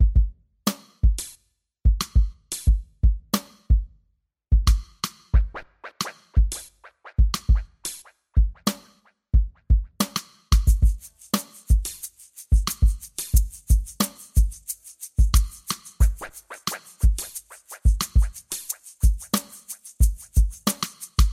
90 Bpm鼓
描述：电鼓
Tag: 90 bpm Electronic Loops Drum Loops 3.59 MB wav Key : Unknown